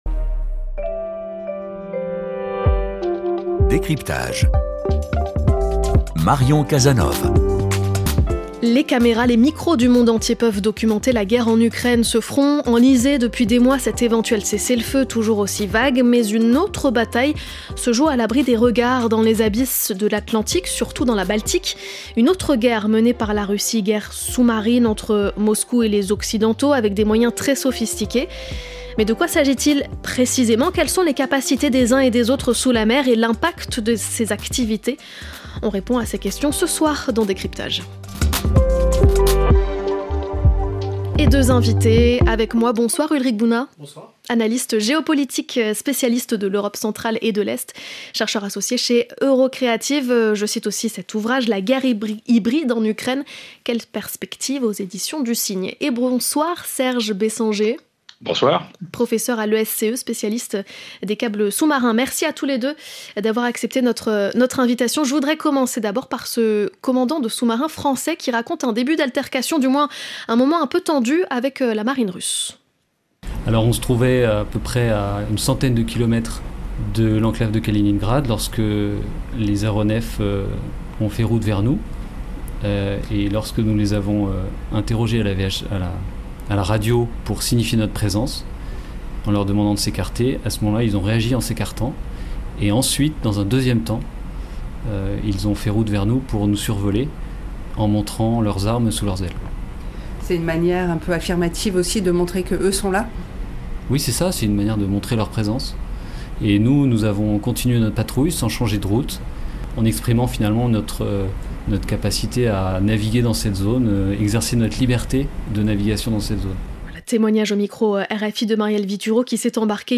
Le décryptage du fait d’actualité du jour avec un à trois spécialistes invités pour contextualiser, expliquer et commenter.